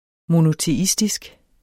Udtale [ monoteˈisdisg ]